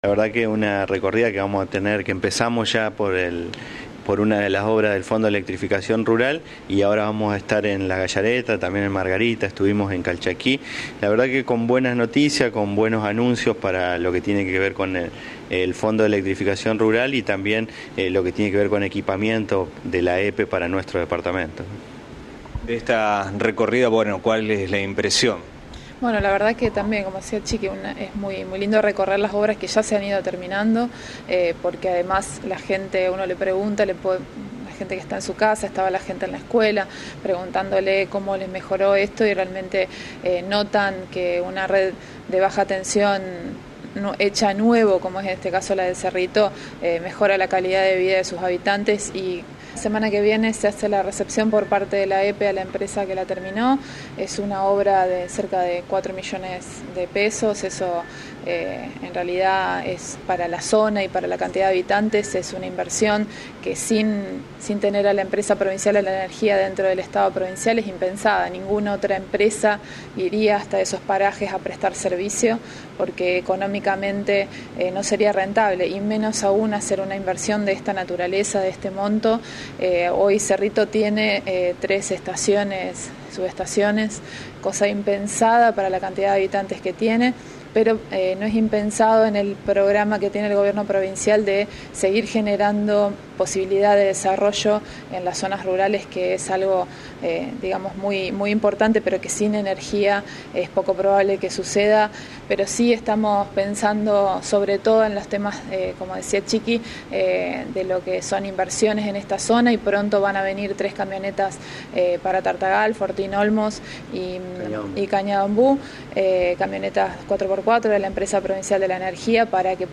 AUDIO: Sergio Rojas (Coordinador Plan del Norte) y Verónica Geese (Secretaria de Energía)